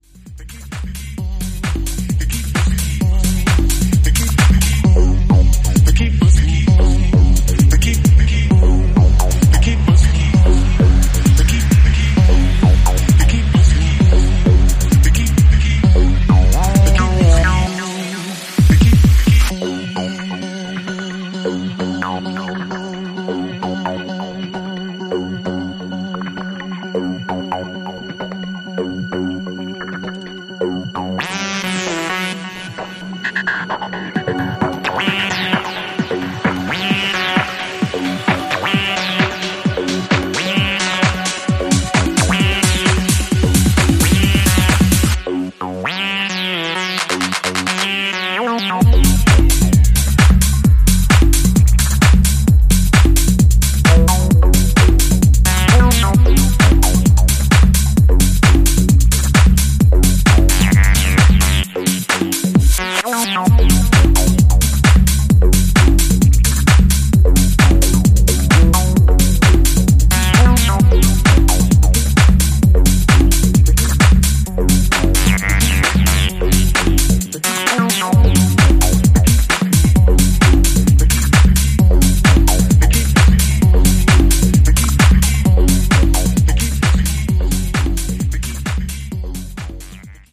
グルーヴィーなミニマル・テック・ハウスを展開
不穏なアシッド使いやシンセリフ、それらを強調したブレイク等で現行のトレンドにアプローチしているのが特に印象的です